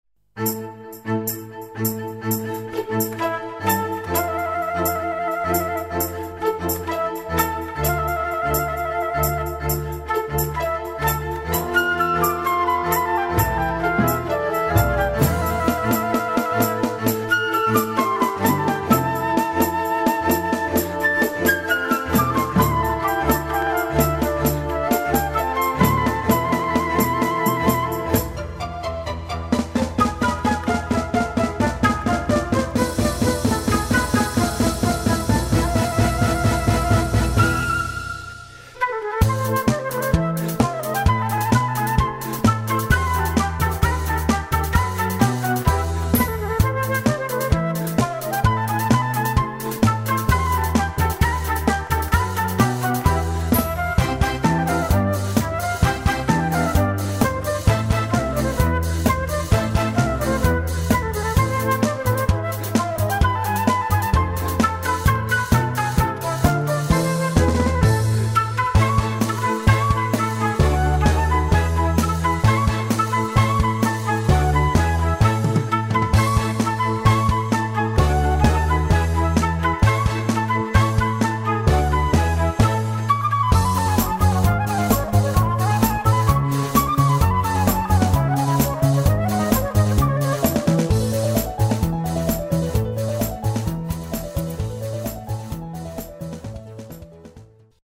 Bitte beachten Sie, dass die Demos Livemitschnitte sind und daher keine Studioqualität haben.